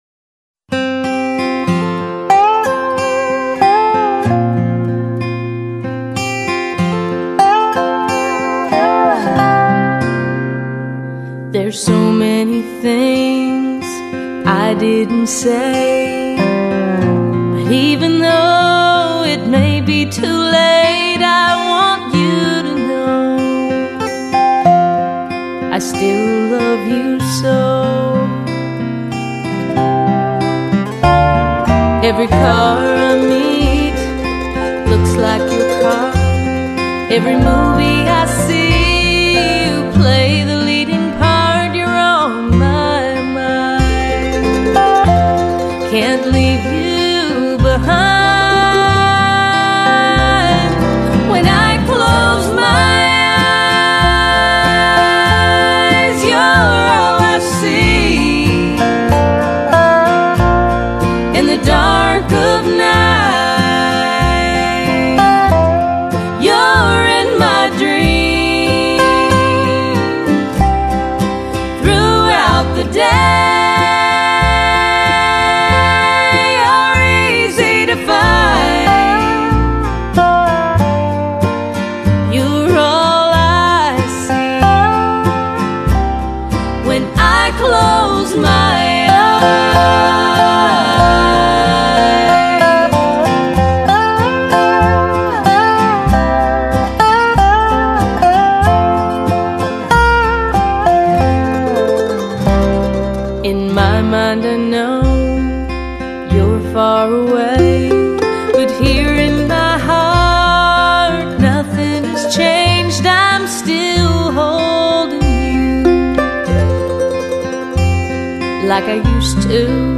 人声
夏日最清凉的声音
这张专辑对于录音的品质也非常重视，每首曲子都有音响示范等级的效果，制作精良让人印象深刻。